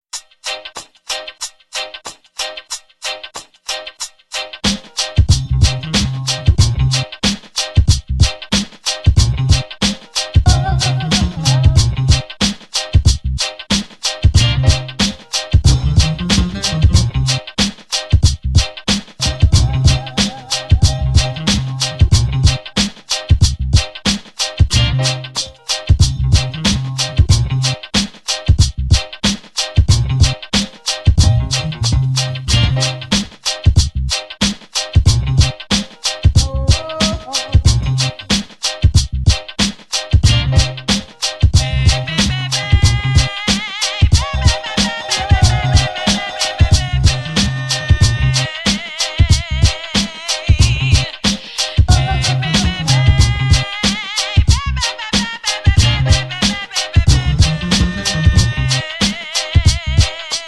Original Instrumental Composition